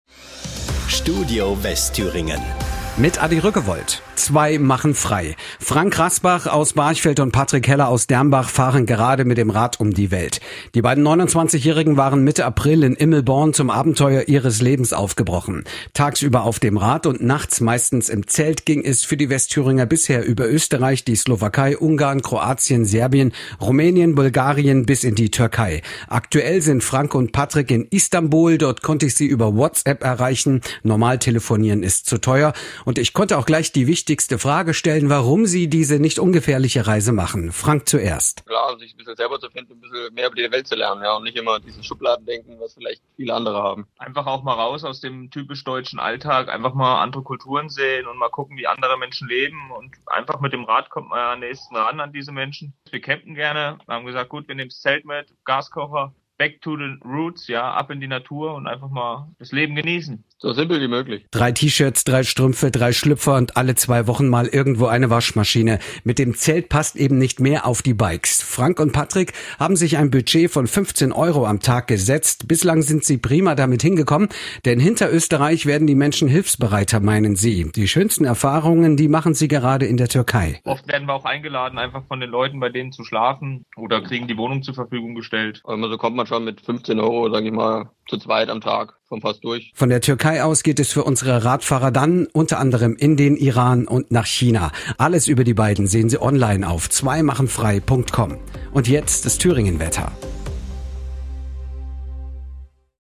Unser Zusammenschnitt  lief bereits mehrmals in den Nachrichten im Radio.